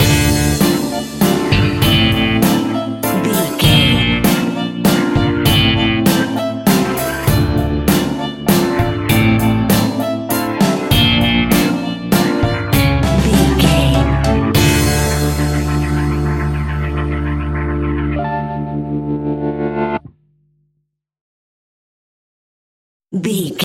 Aeolian/Minor
tension
ominous
dark
eerie
electric guitar
violin
piano
strings
bass guitar
drums
percussion
horror music